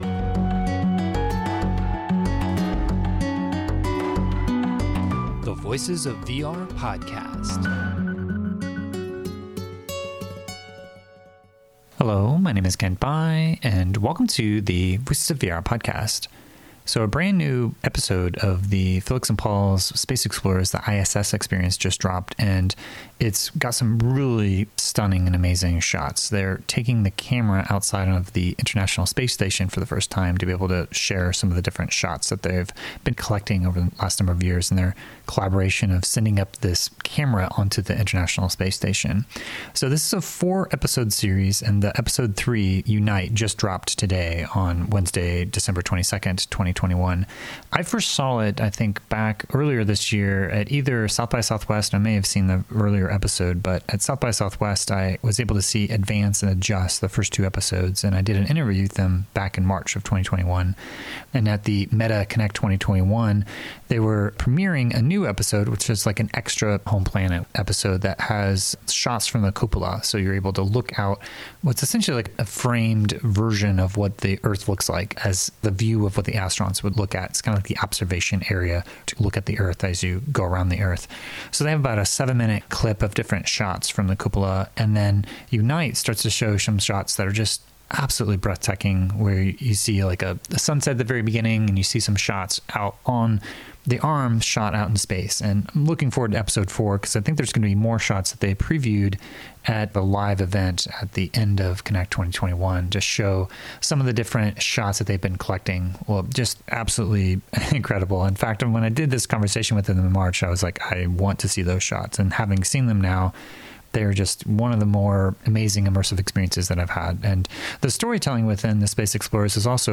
and I had a chance to speak to them after the premiere of the 2nd episode at SXSW in March 2021.